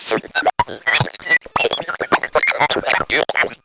数学 拼写 " gigity gigity hit hit
描述：这是我弯曲的Ti Math Spell的一些随机废话的简短样本。标题是我描述声音的最佳努力。
标签： 模拟 弯曲 电路 音素 随机 拼写
声道立体声